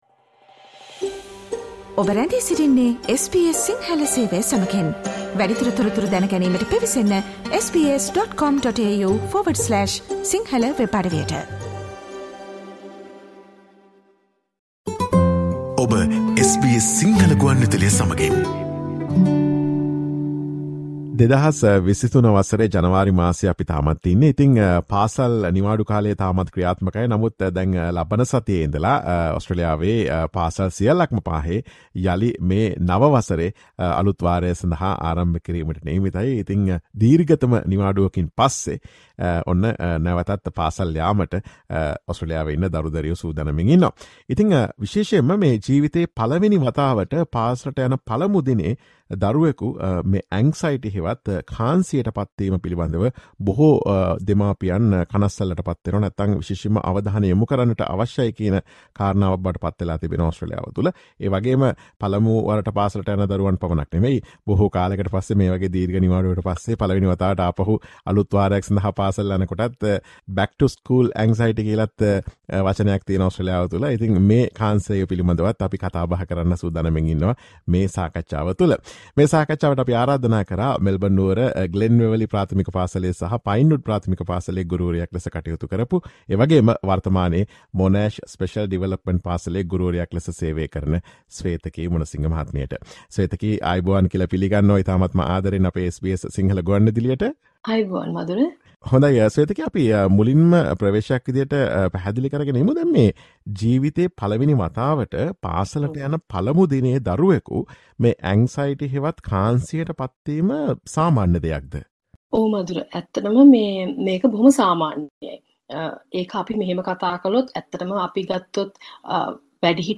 පාසල් යන පළමු දිනයේදී දරුවන්ට ඇතිවන Separation Anxiety හෙවත් දෙමාපියන්ගෙන් වෙන්වීමේ කාංසාව වළක්වා ගැනීම සඳහා දෙමාපියන්ට ගත හැකි පියවර පිළිබඳ SBS සිංහල ගුවන් විදුලිය සිදුකළ සාකච්ඡාවට සවන් දෙන්න.